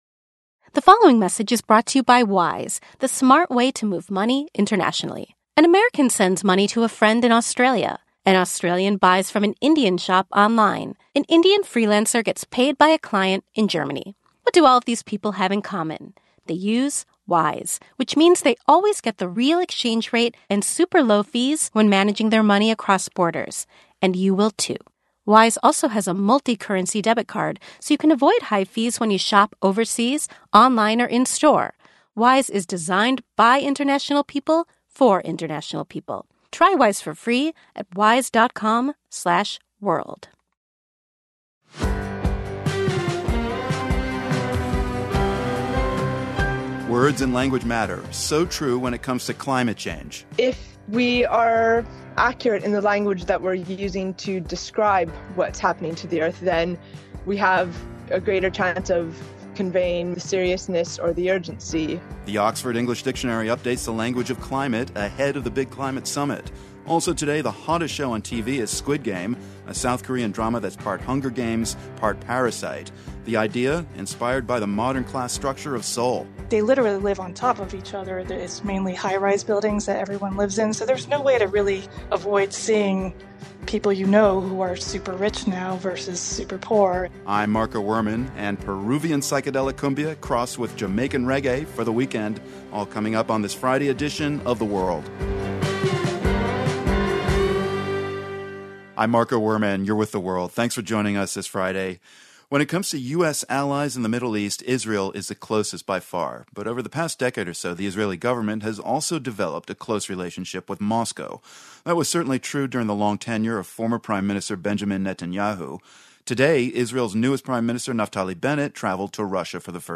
Over the past decade, the Israeli government has been cozying up to Moscow. On Friday, new Israeli Prime Minister Naftali Bennett traveled to Russia for the first time and met with President Vladimir Putin at a resort in Sochi, Russia, to discuss Israel and Russia's “special relationship.” Also, the Netflix series “Squid Game” is a dark comedy about a competition that emerges from Korean culture, but has widespread appeal. We speak to a psychiatrist who explains why the new show resonates so far and wide beyond South Korea.